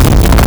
Player_Glitch [88].wav